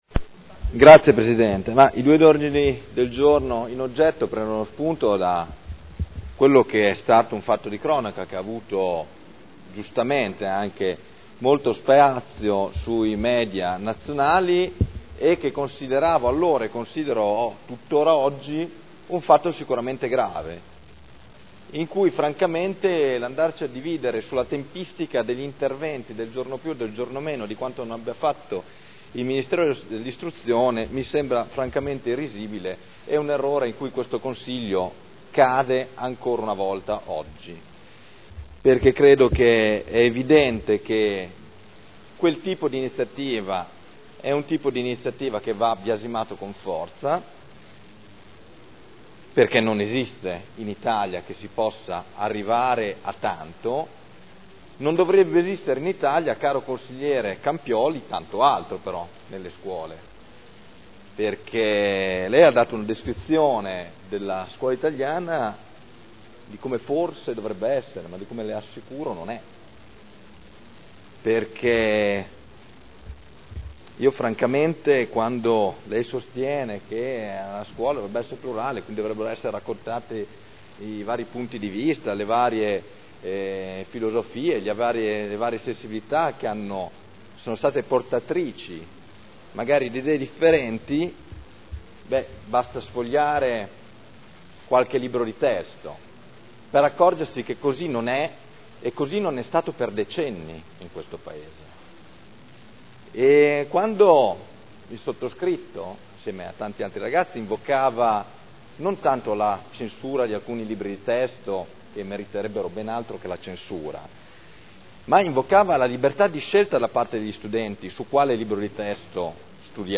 Michele Barcaiuolo — Sito Audio Consiglio Comunale